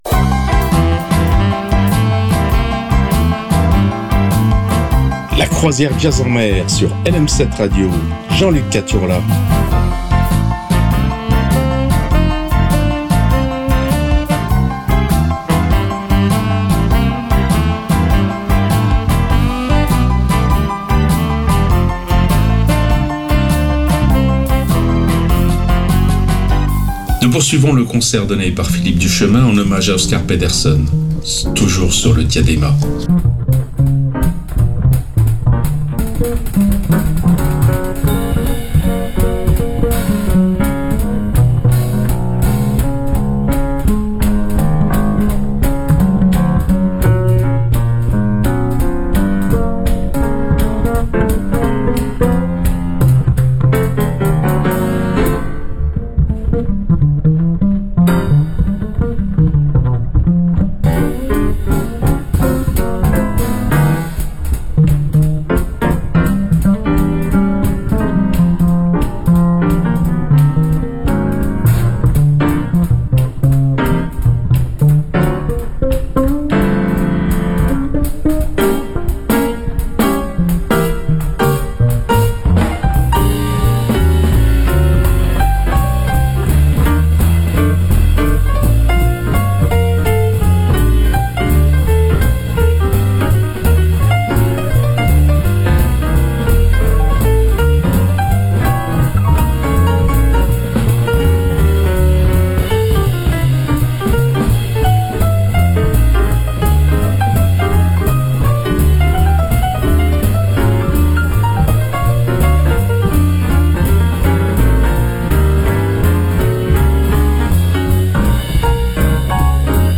sur des rythmes jazzy